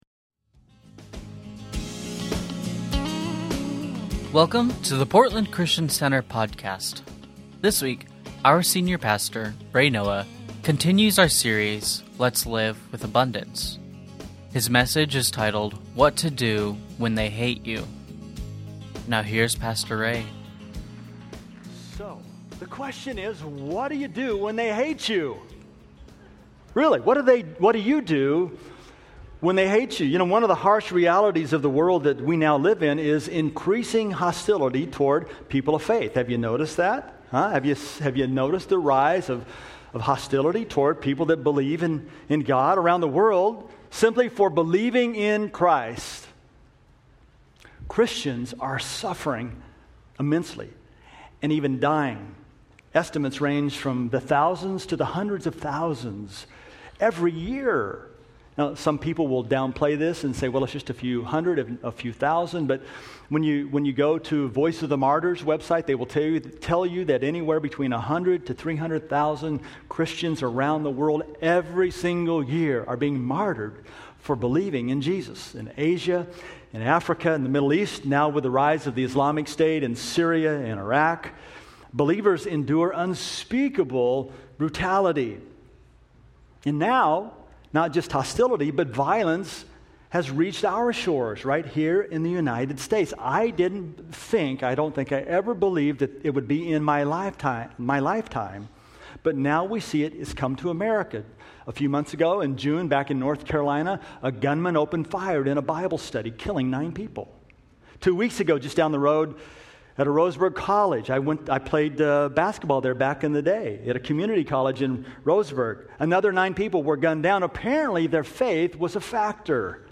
Sunday Messages from Portland Christian Center What To Do When They Hate You Oct 11 2015 | 00:30:30 1x 00:00 / 00:30:30 Subscribe Share Spotify RSS Feed Share Link Embed